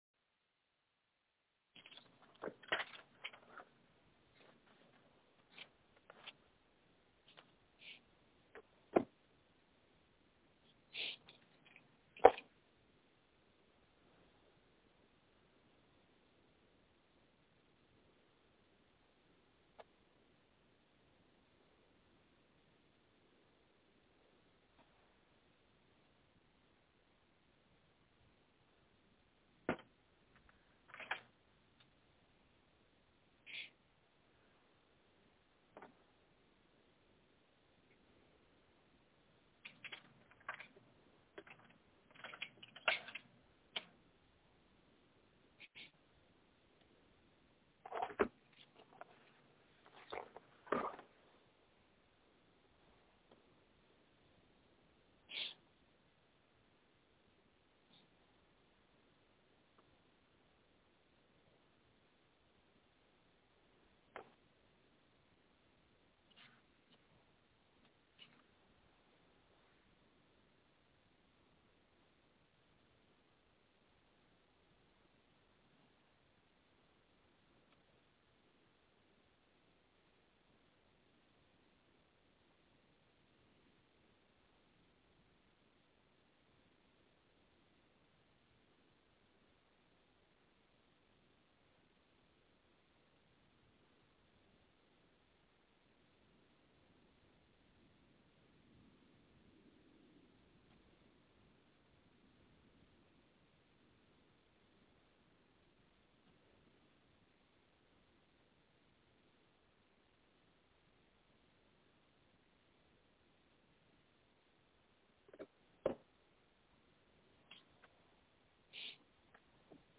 Coach and athlete chat